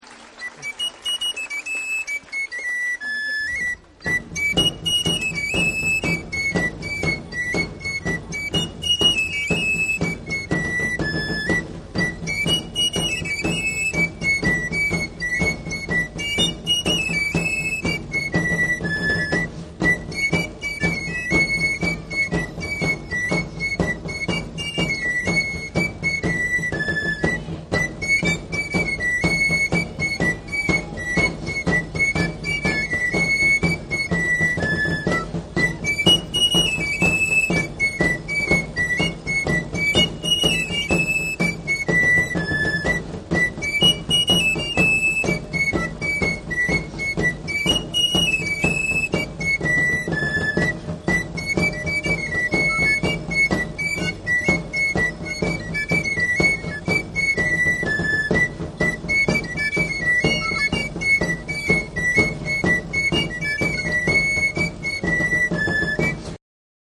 • Musiques folkloriques :
Extraits de musiques joués par nos tambourinaires lors de spectacles :
4. Farandole :